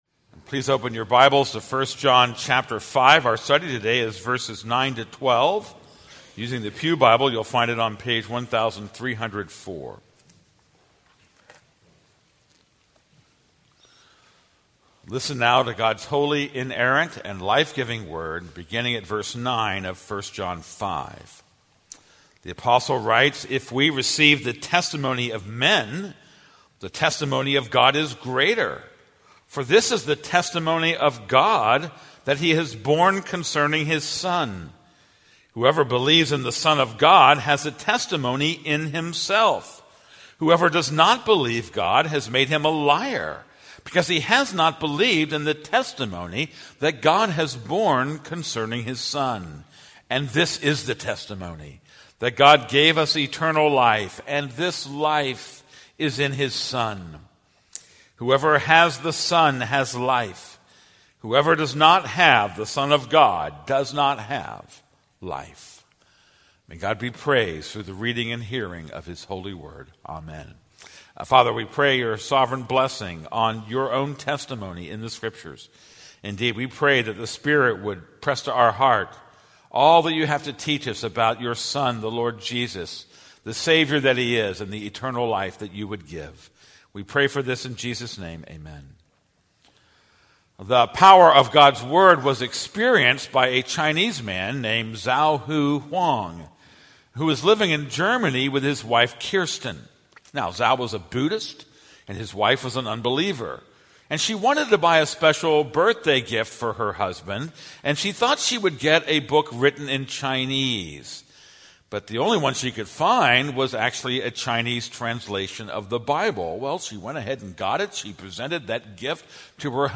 This is a sermon on 1 John 5:9-12.